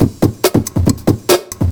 ELECTRO 10-L.wav